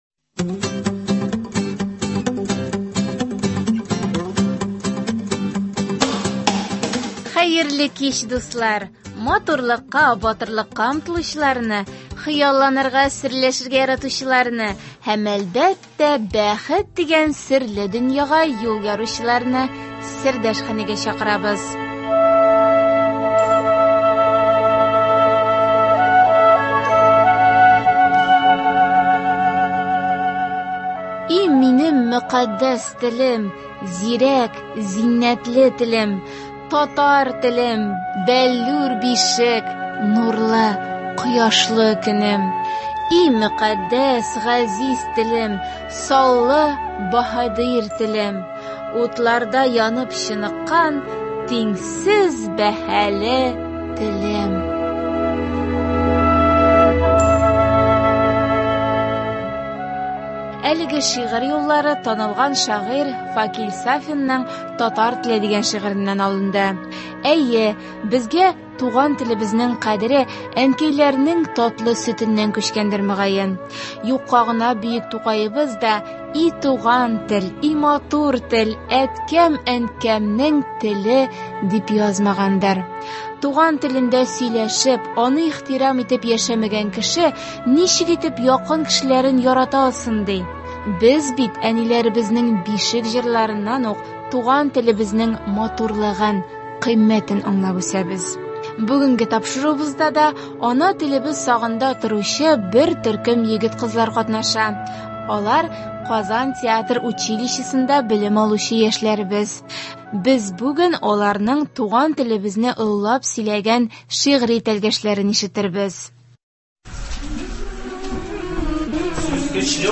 Бүгенге тапшыруыбызда ана телебез сагында торучы бер төркем егет – кызлар катнаша. Аларның туган телебезне олылап сөйләгән шигьри тәлгәшләрен ишетербез.